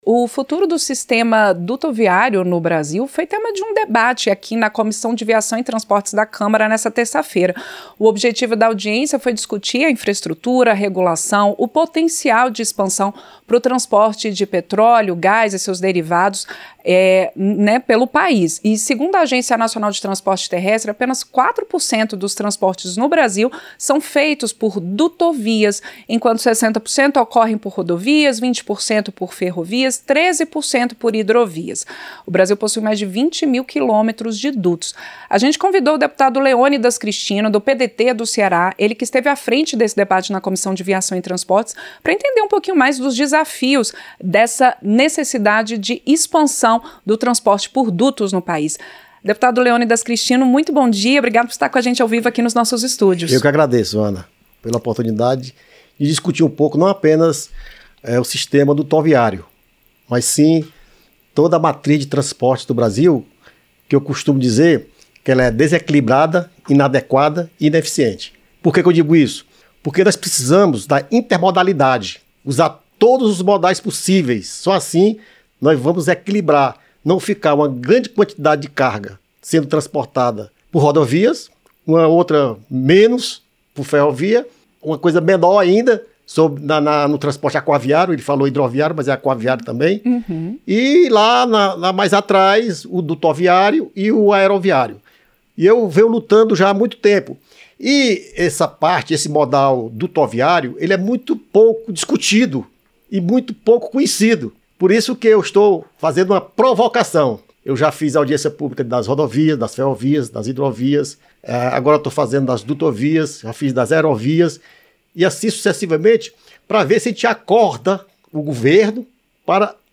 Entrevista - Dep. Leônidas Cristino (PDT-CE)